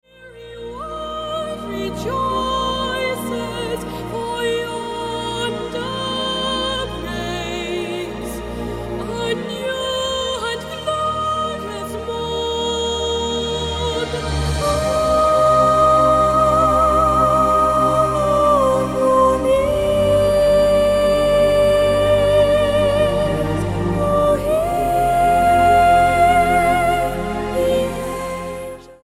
MOR / Soft Pop
Six British choristers